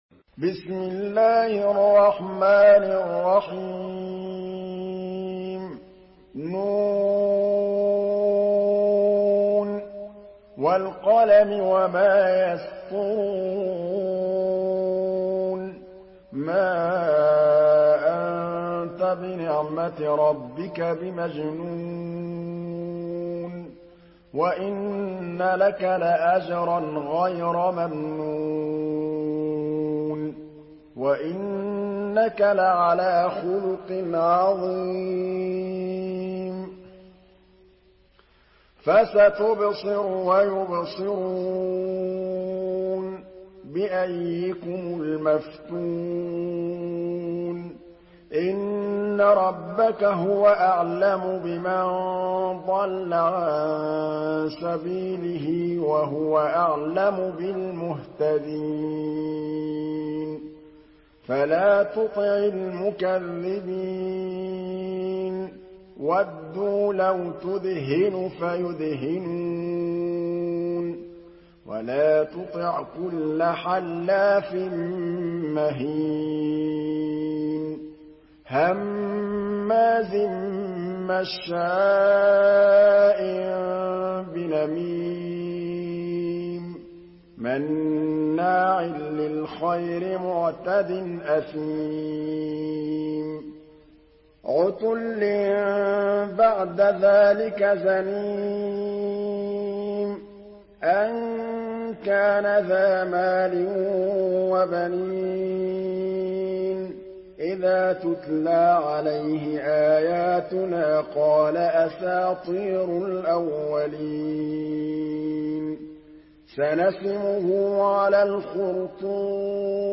Surah القلم MP3 by محمد محمود الطبلاوي in حفص عن عاصم narration.
مرتل حفص عن عاصم